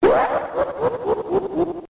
Категория: смех